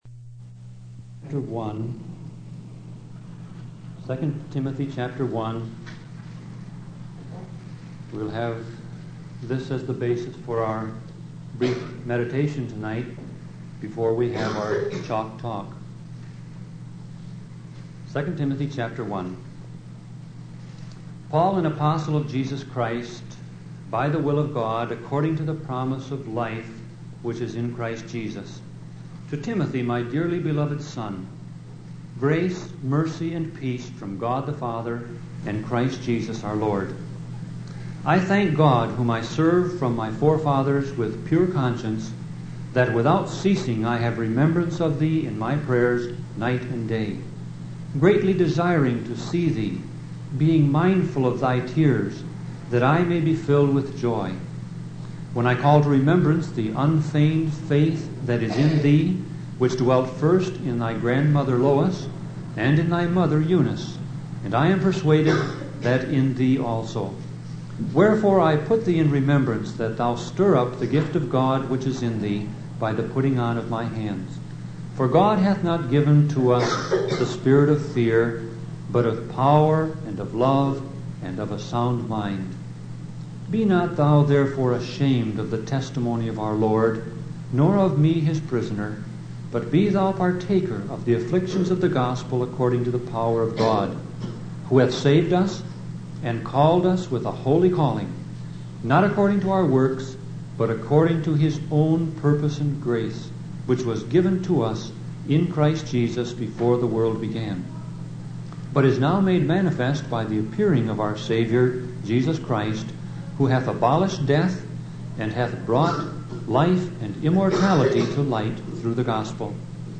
Sermon Audio Passage: 2 Timothy 1 Service Type